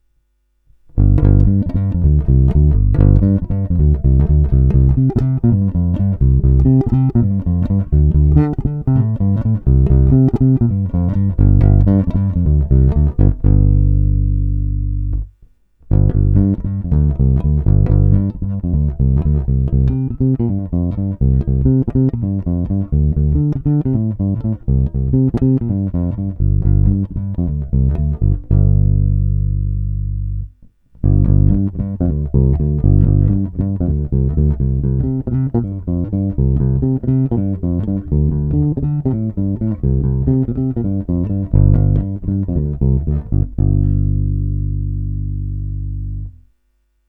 Není-li uvedeno jinak, jsou provedeny rovnou do zvukovky s plně otevřenou tónovou clonou a jen normalizovány, jinak bez dalších úprav.
Nahrávka ve stejném pořadí jako výše